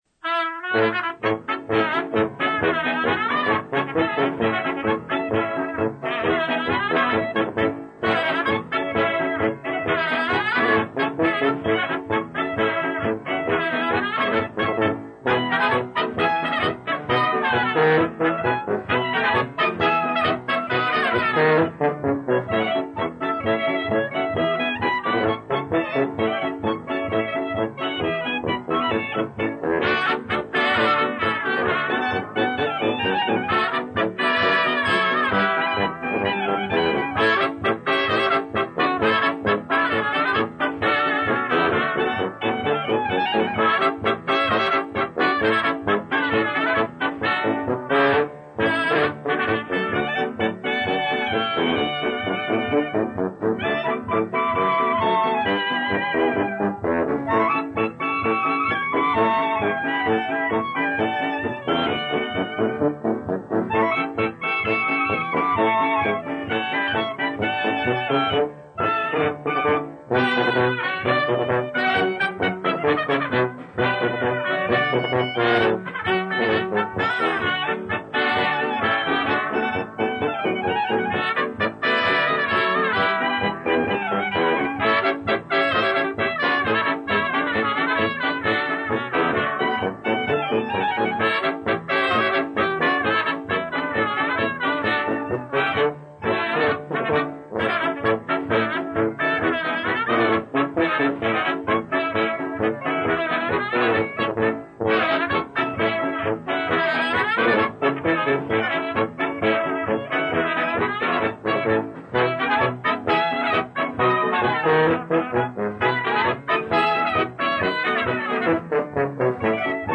Polka
Waltz
Commentary